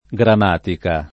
grammatica [gramm#tika] (antiq. gramatica [